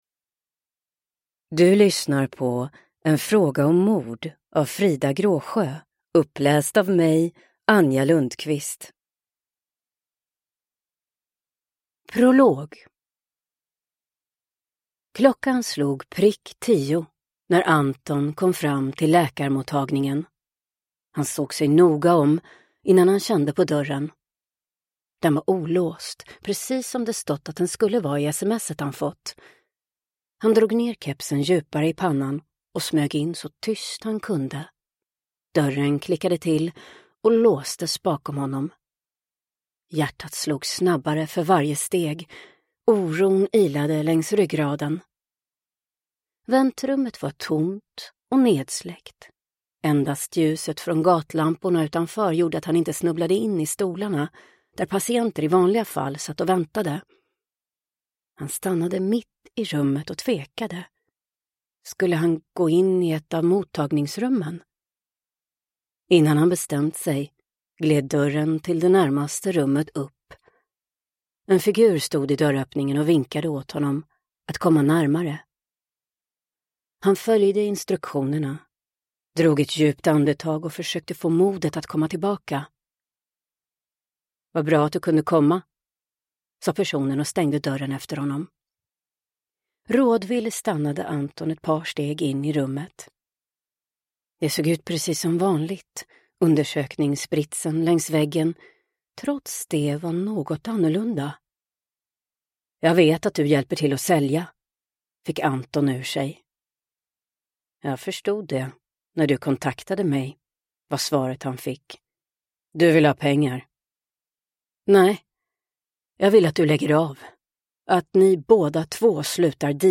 En fråga om mord / Ljudbok